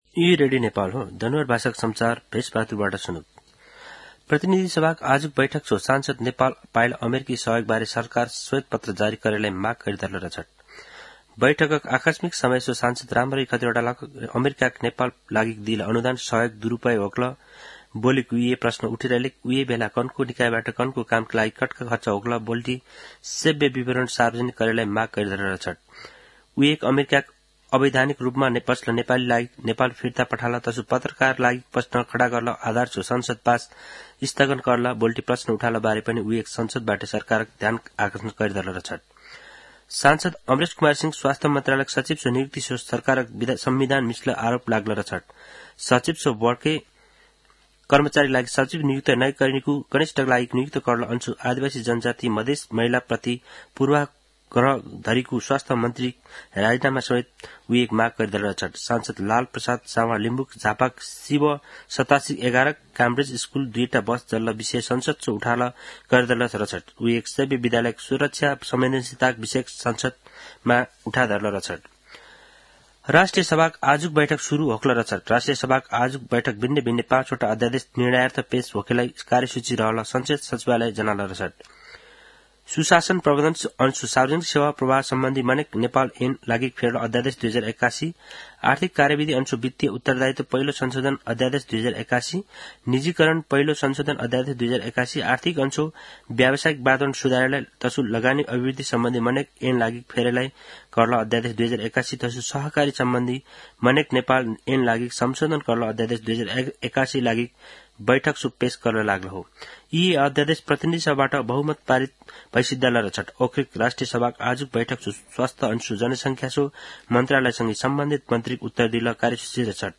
An online outlet of Nepal's national radio broadcaster
दनुवार भाषामा समाचार : २३ फागुन , २०८१
danuwar-news.mp3